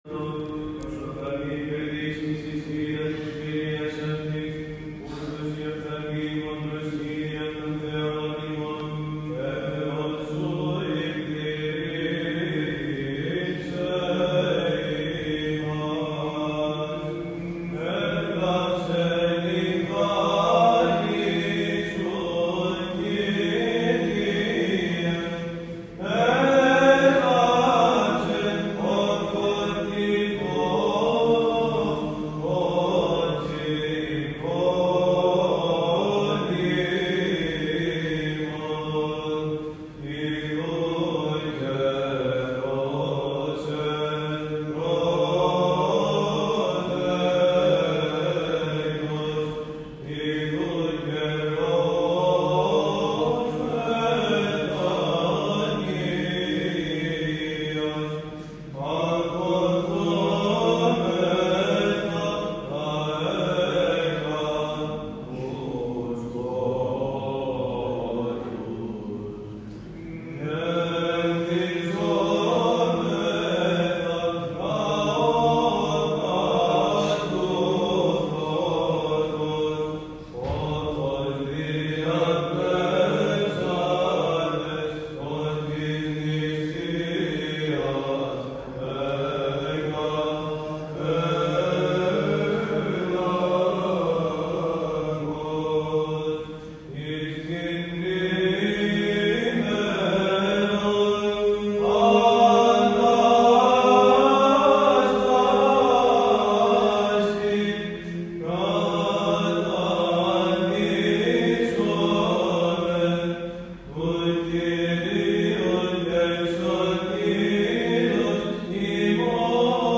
Στον Εσπερινό της Συγχωρήσεως, που τελέστηκε με κατάνυξη και ιεροπρέπεια στον Ιερό Μητροπολιτικό Ναό του Αγίου Γεωργίου Νεαπόλεως, χοροστάτησε ο Σεβασμιώτατος Μητροπολίτης Νεαπόλεως και Σταυρουπόλεως κ. Βαρνάβας το απόγευμα της Κυριακής 26 Φεβρουαρίου 2023.
Παρατίθεται ηχητικό αρχείο με βυζαντινούς ύμνους του Εσπερινού τους οποίους απέδωσε  ο αριστερός χορός του Μητροπολιτικού Ναού: